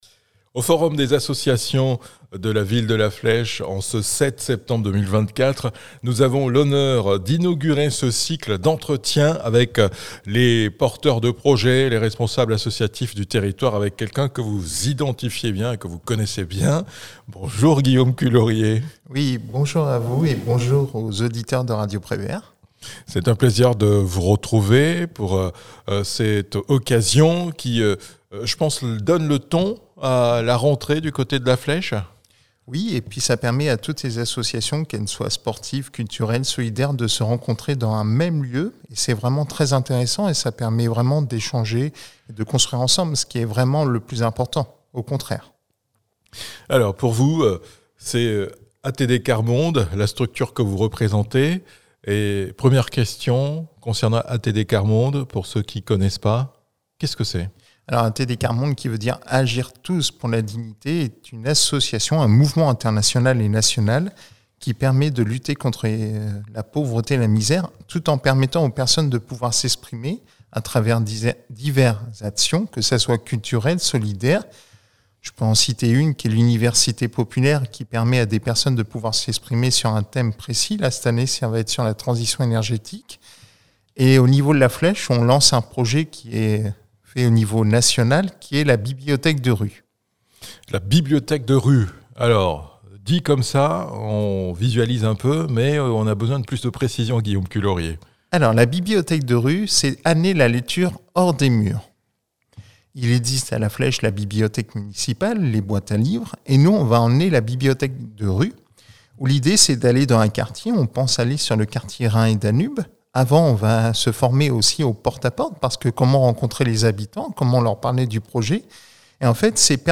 D'où vient l'idée, comment et quand va-t-elle se concrétiser ? Entretien